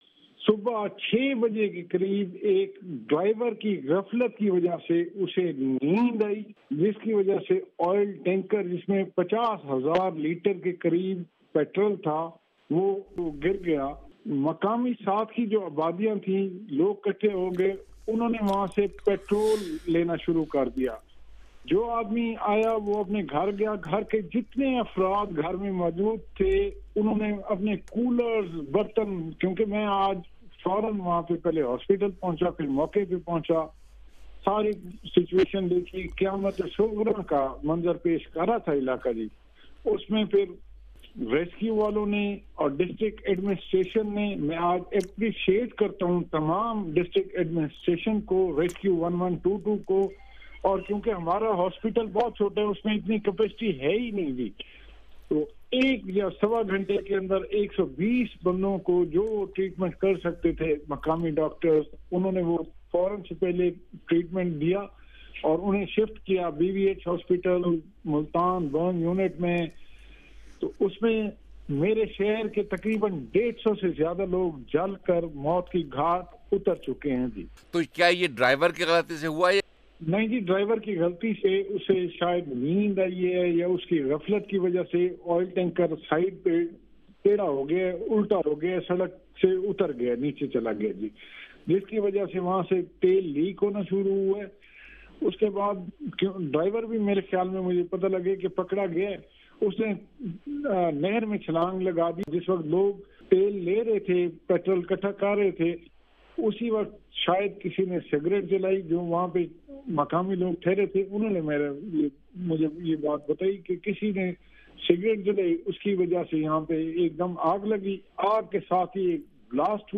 احمد پور شرقیہ کے سانحے کے بارے میں 'وائس آف امریکہ' کے پروگرام 'جہاں رنگ' میں علاقے کے رکن قومی اسمبلی سید علی گیلانی اور حکومت پنجاب کے ترجمان ملک احمد خان سے گفتگو